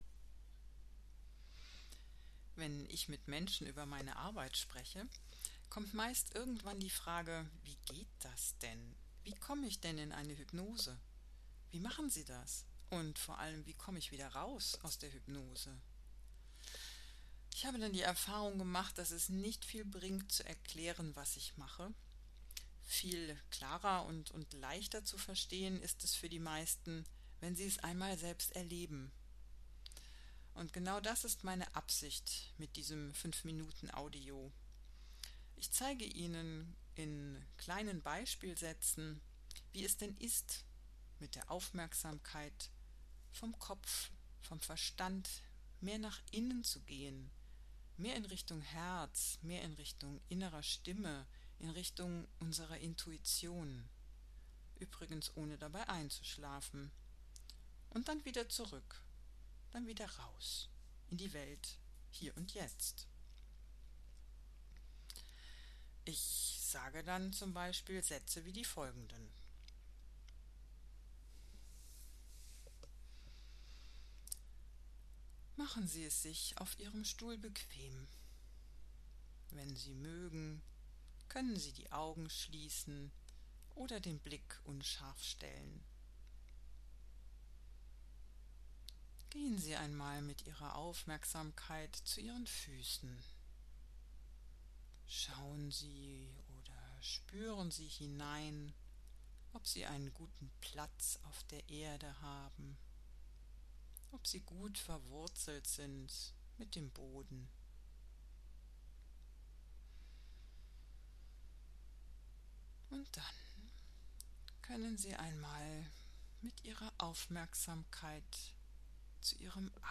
Beispiel-fuer-Hypnoseeinleitung-mp3_kompr.mp3